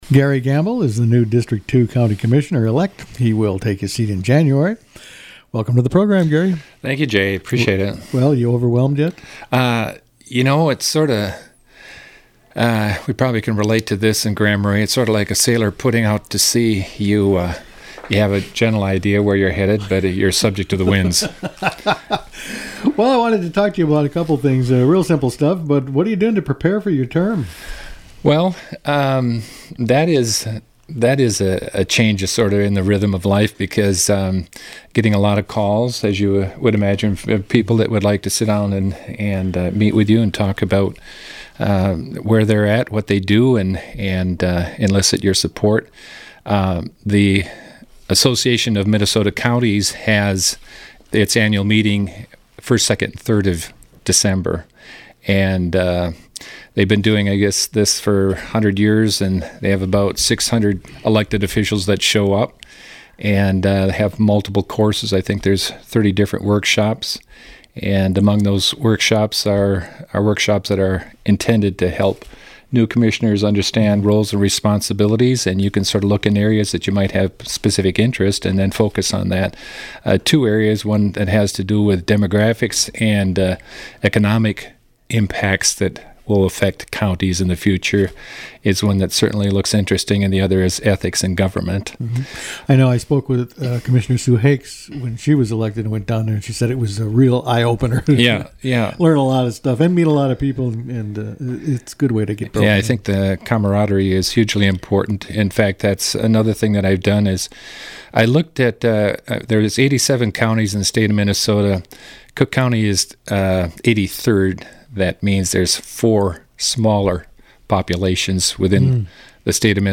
Commissioner-elect Garry Gamble talks about the future